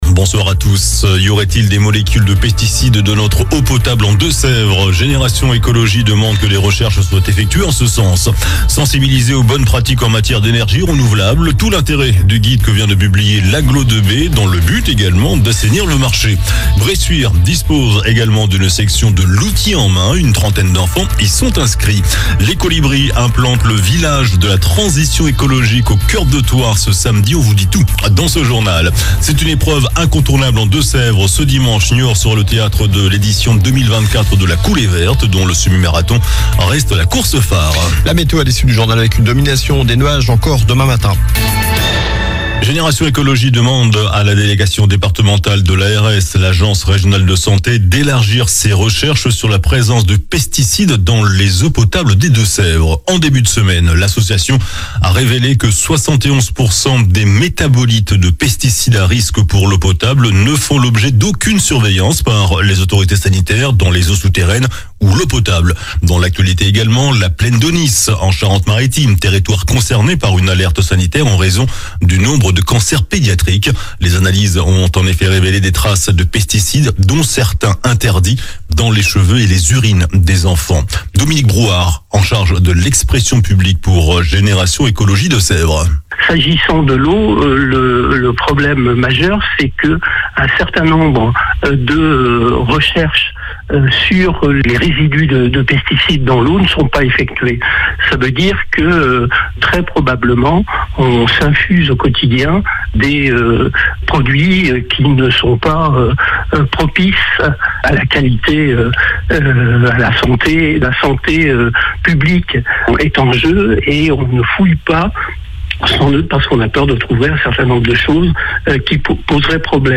JOURNAL DU JEUDI 17 OCTOBRE ( SOIR )